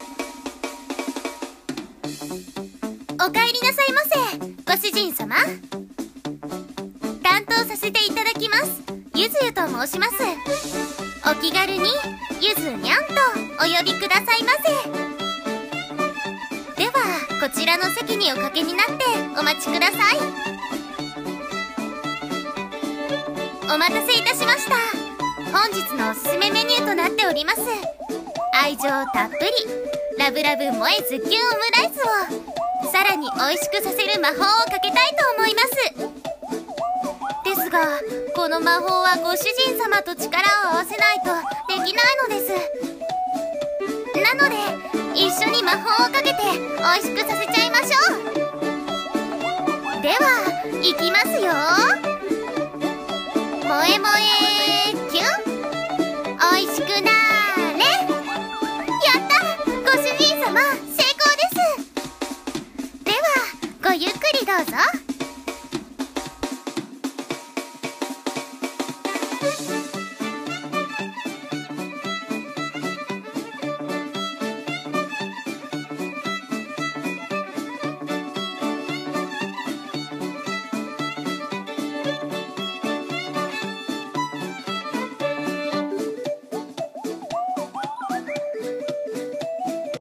【声劇朗読】